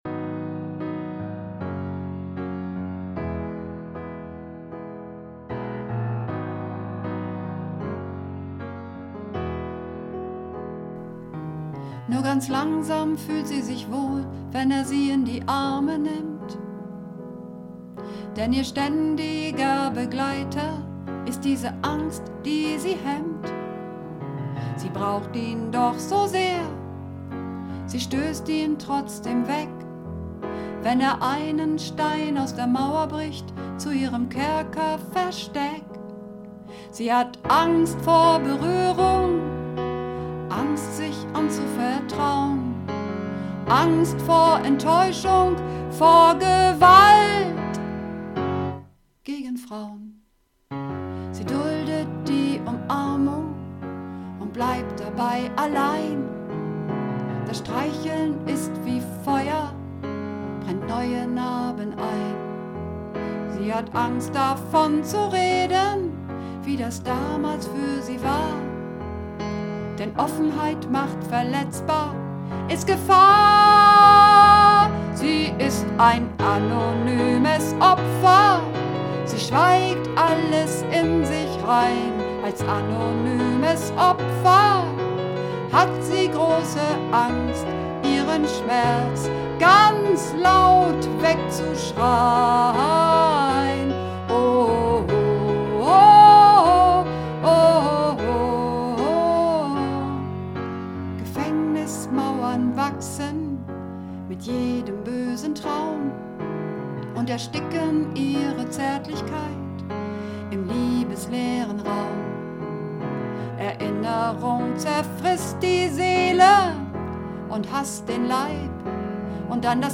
Übungsaufnahmen - Anonyme Opfer
Anonyme Opfer (Alt und Sopran)